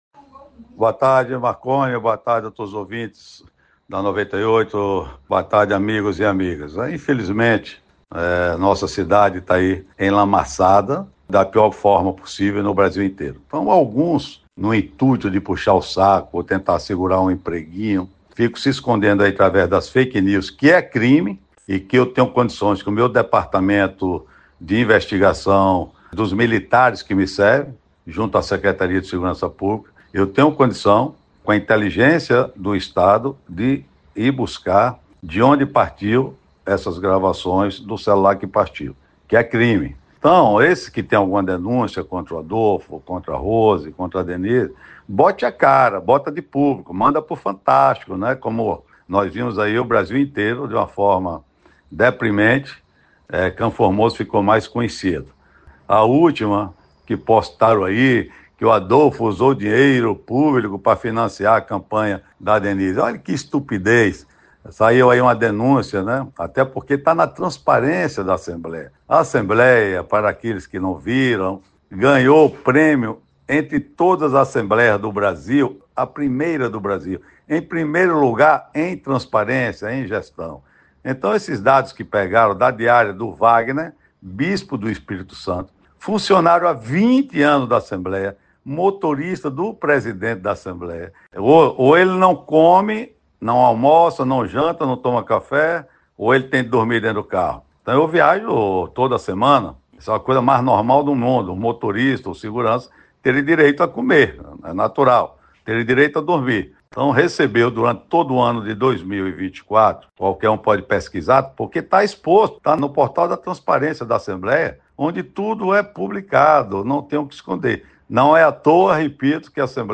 Deputado Estadual Adolfo Menezes – comenta sobre denúncia relacionada à diária de motorista da ALBA